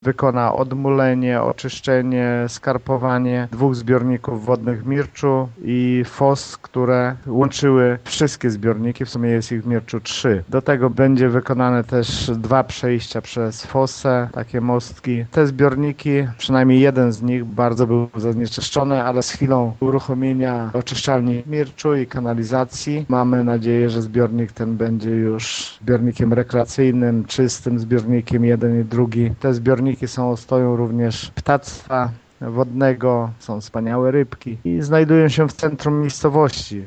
Jak mówi wójt Lech Szopiński, na razie firma, która wygrała przetarg: